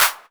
• Dry Clap Sample F Key 27.wav
Royality free clap sound - kick tuned to the F note. Loudest frequency: 6112Hz
dry-clap-sample-f-key-27-RVi.wav